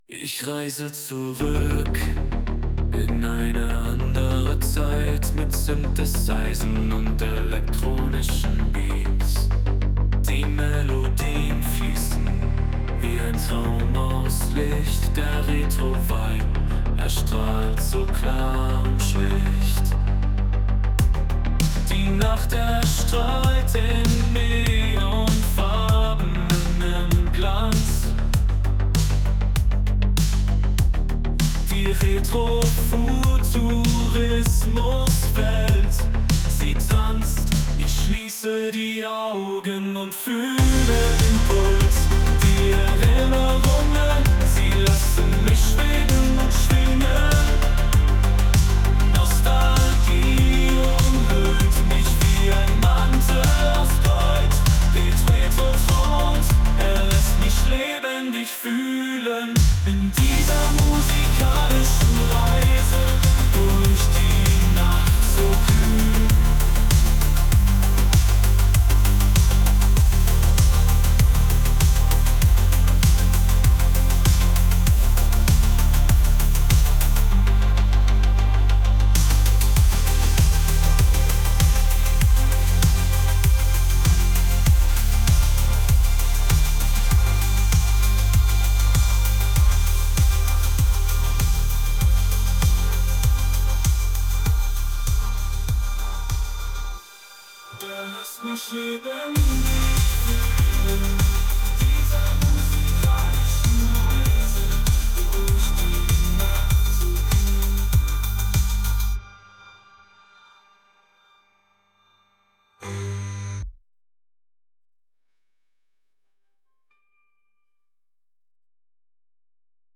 AI Schlager
Experience the best of AI-generated Schlager music.